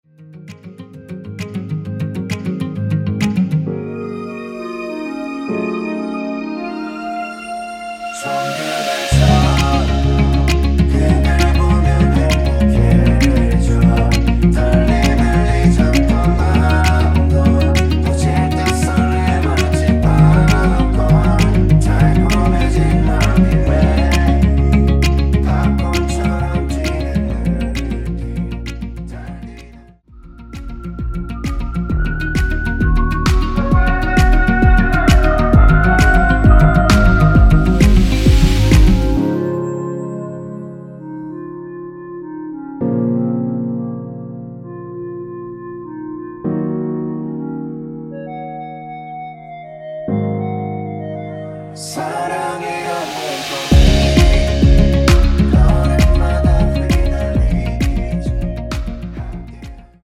원키에서(-1)내린 멜로디와 코러스 포함된 MR입니다.
Db
앞부분30초, 뒷부분30초씩 편집해서 올려 드리고 있습니다.
중간에 음이 끈어지고 다시 나오는 이유는